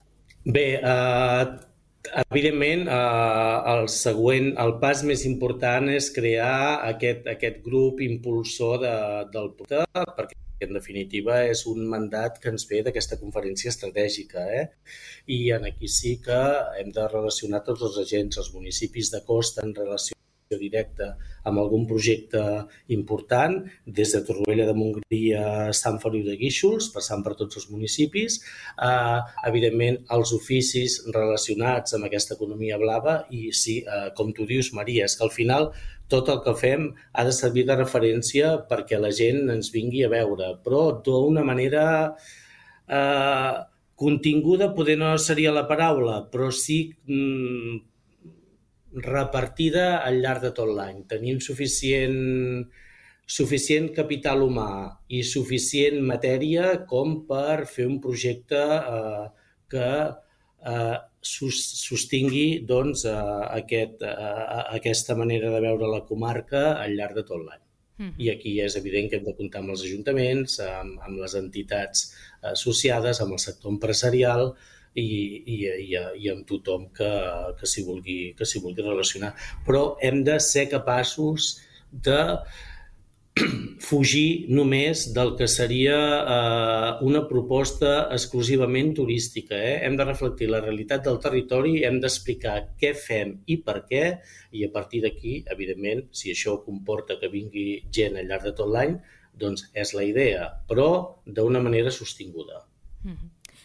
En una entrevista a Ràdio Capital, ha detallat els reptes principals del seu mandat: impulsar l’economia blava, consolidar un hub cultural comarcal, millorar la mobilitat i afrontar la gestió dels residus a la comarca.